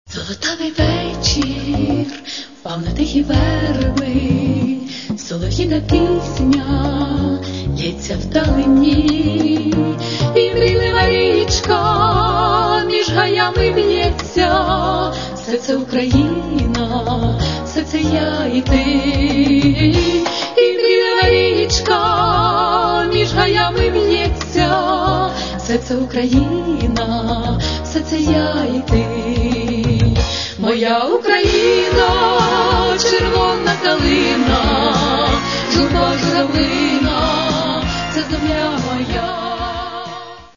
Каталог -> Естрада -> Дуети